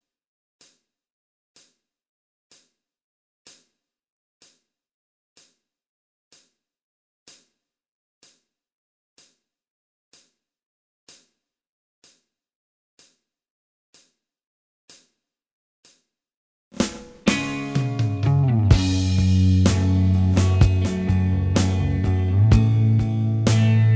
Minus Acoustic Soft Rock 3:27 Buy £1.50